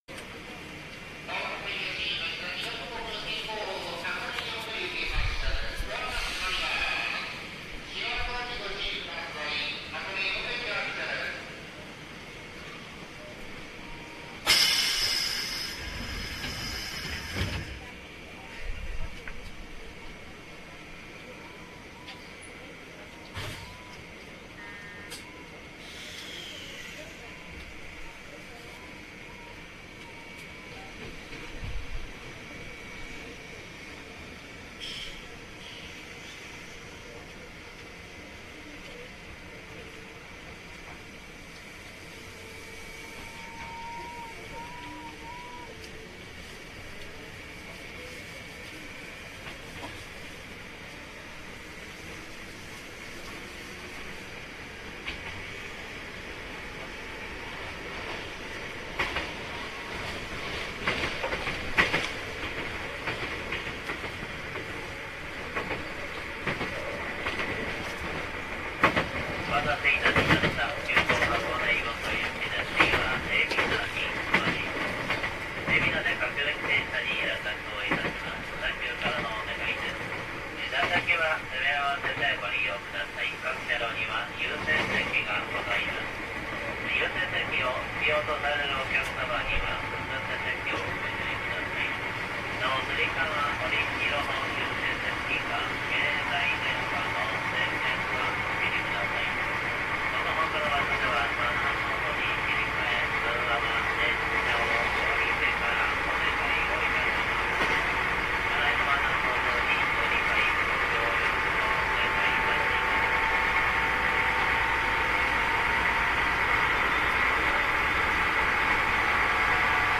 勢いよく閉まるドア、あっという間に上がる音量、そして2600形にはない迫力のある音です。
こちらはやや音量控えめで、割と最近に更新工事を受けたのでまだまだ活躍するんだろうな思ったものです。
凄いフラット音と共にお楽しみください（？）
小田急線急行　相模大野〜海老名（9402）